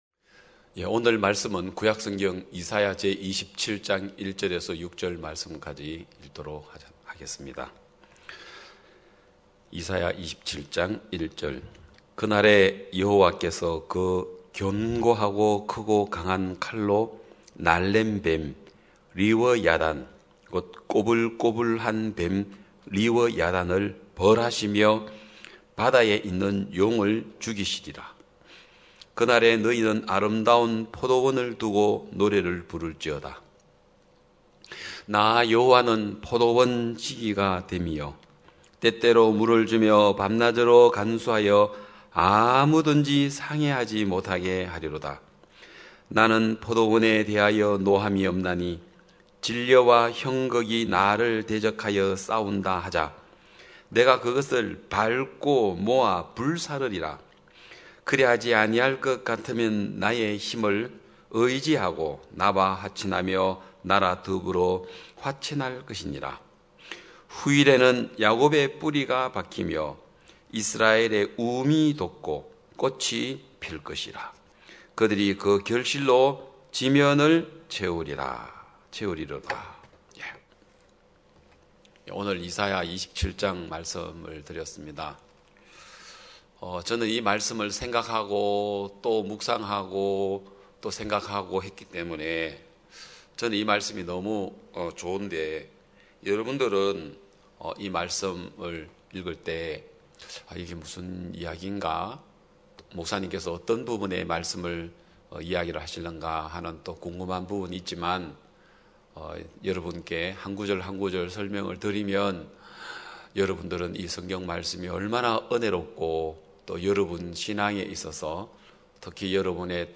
2021년 8월 8일 기쁜소식양천교회 주일오전예배
성도들이 모두 교회에 모여 말씀을 듣는 주일 예배의 설교는, 한 주간 우리 마음을 채웠던 생각을 내려두고 하나님의 말씀으로 가득 채우는 시간입니다.